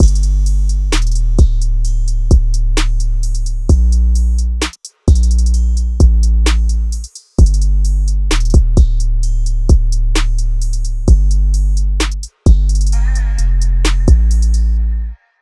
drumloop 13 (130 bpm).wav